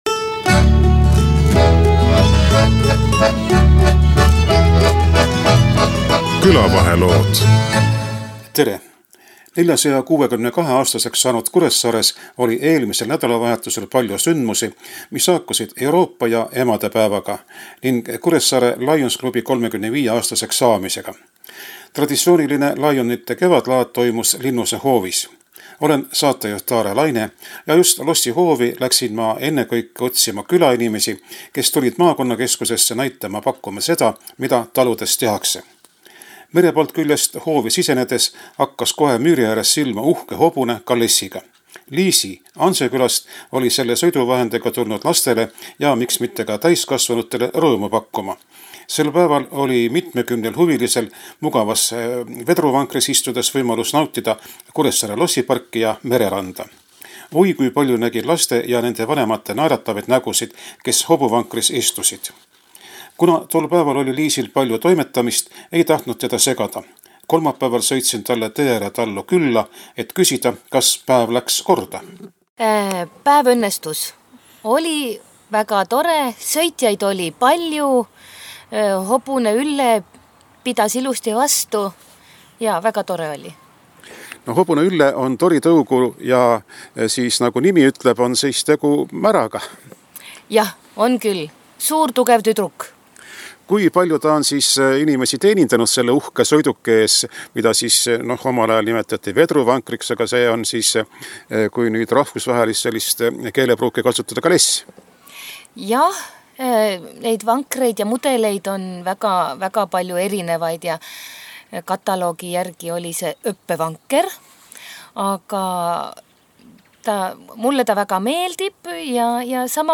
küsitles sel päeval, millal tähistati ka Euroopa päeva ja Kuressaare Lions klubi 35. tegevusaastat, ettevõtjaid küladest, kes näitasid-pakkusid lionite traditsioonilisel laadal seda, mida külades tehakse.